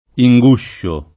inguscio [ i jg2 ššo ]